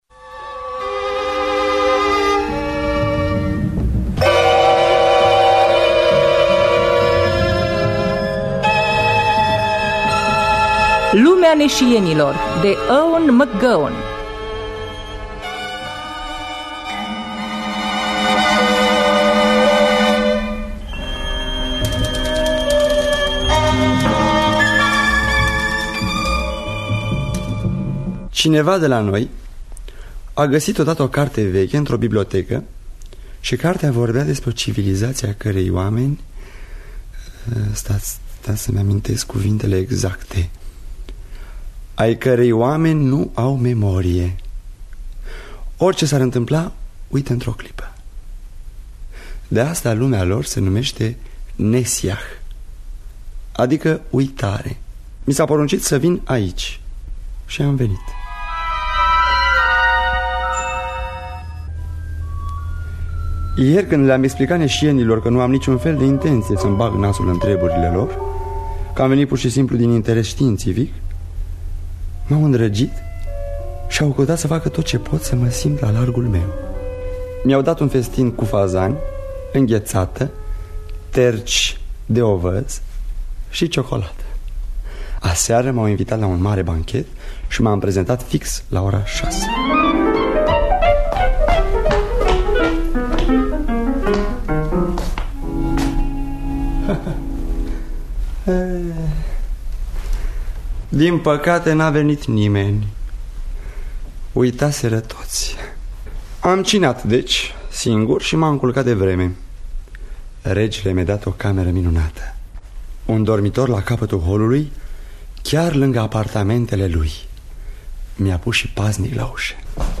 Lumea neshienilor de Ewan Mac Gowen – Teatru Radiofonic Online